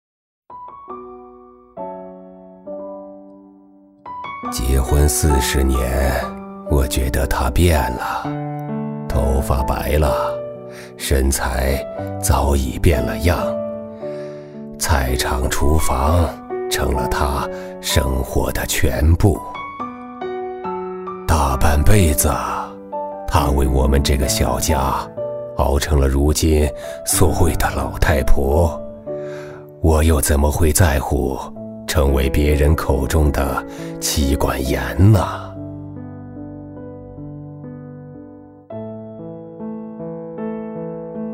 老人配音-配音样音免费在线试听-第1页-深度配音网
男172--老人独白-demo慈祥.mp3